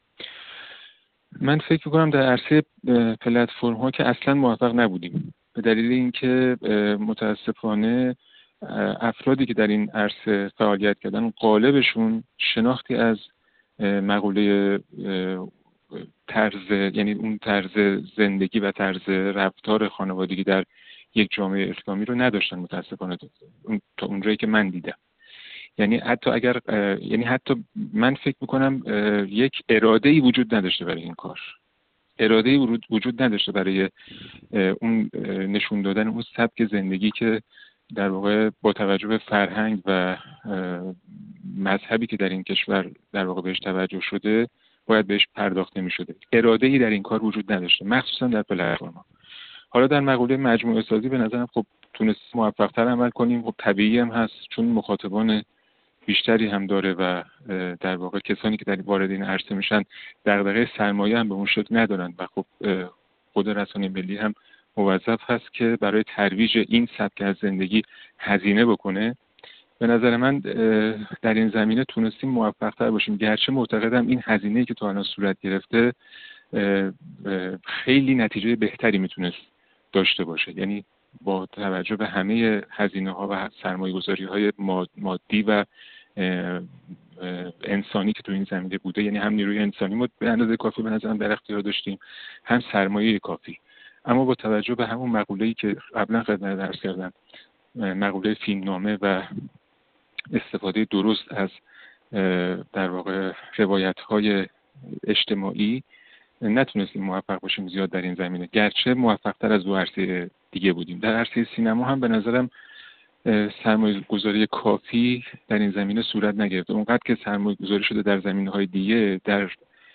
یک کارشناس و منتقد سینما:
گفت‌وگو